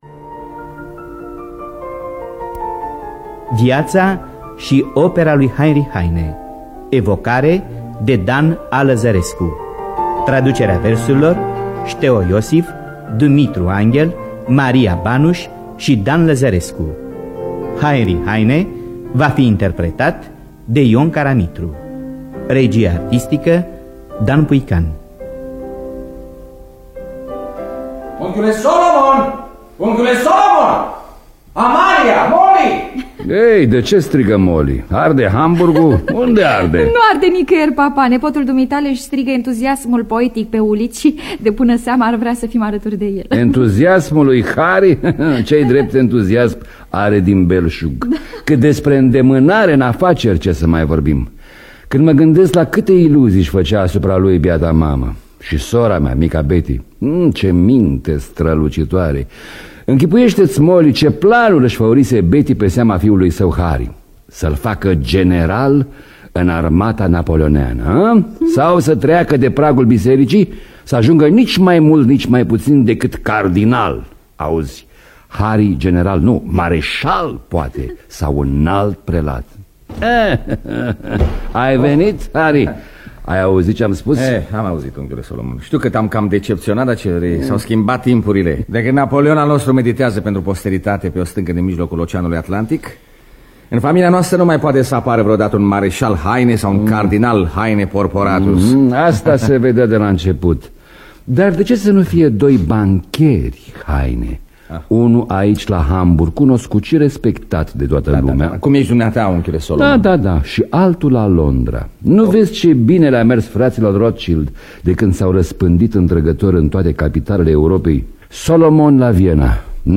Biografii, memorii: Viața și opera lui Heinrich Heine. Scenariu radiofonic de Dan Amedeo Lăzărescu.